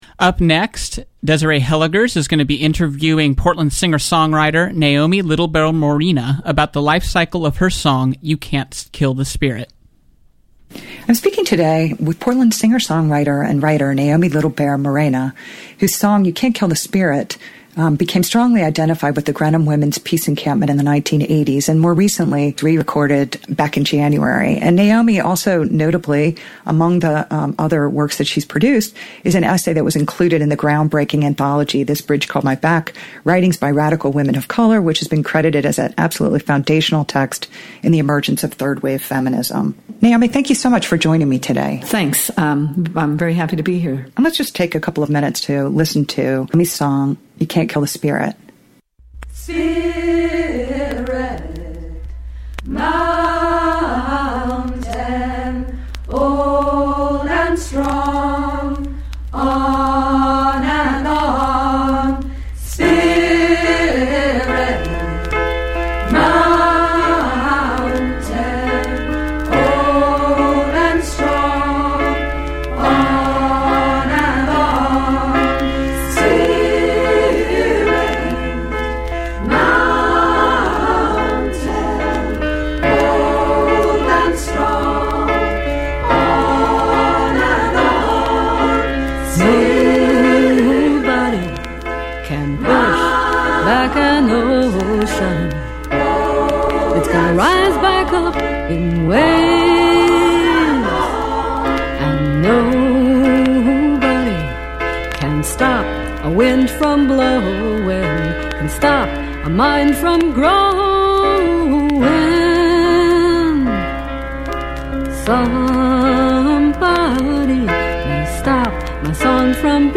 CONVERSATION WITH THE COMPOSER